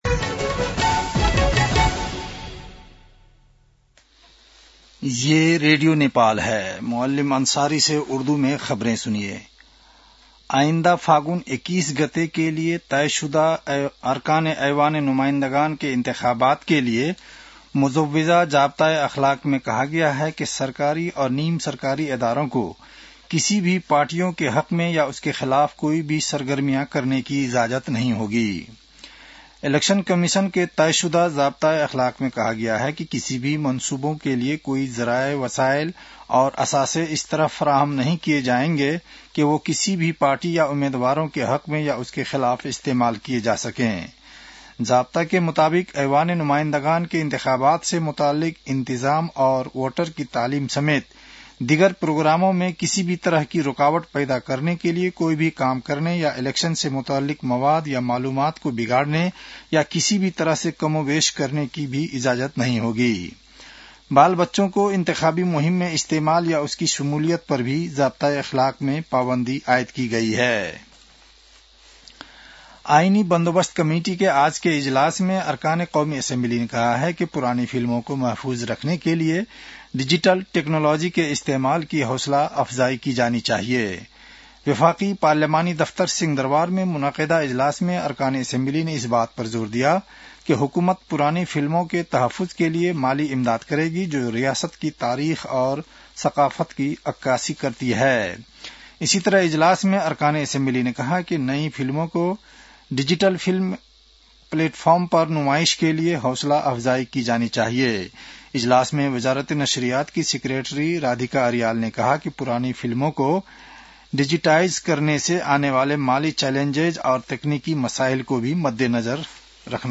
उर्दु भाषामा समाचार : २० पुष , २०८२
Urdu-news-9-20.mp3